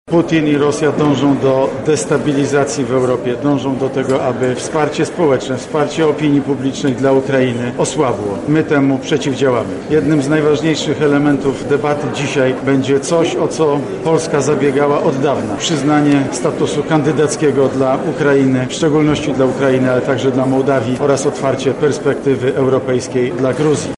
• mówi premier Mateusz Morawiecki.